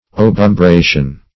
Search Result for " obumbration" : The Collaborative International Dictionary of English v.0.48: Obumbration \Ob`um*bra"tion\, n. [L. obumbratio.]